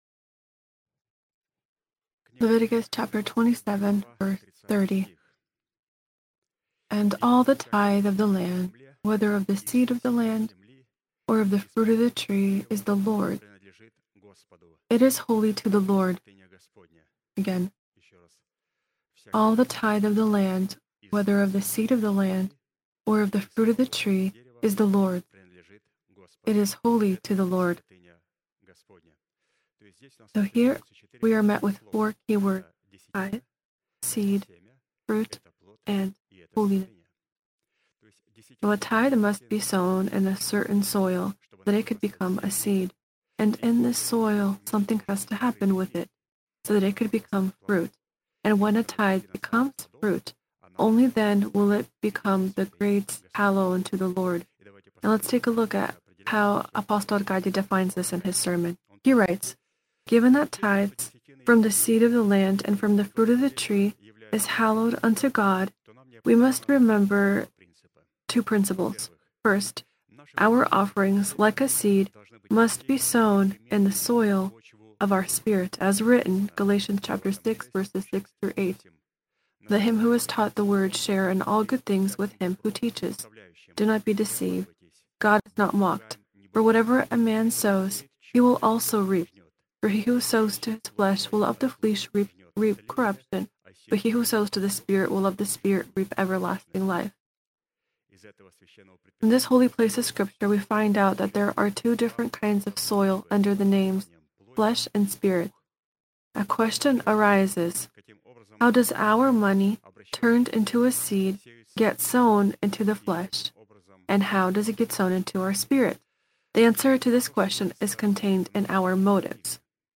Sermon title: